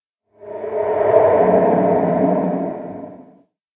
cave13.ogg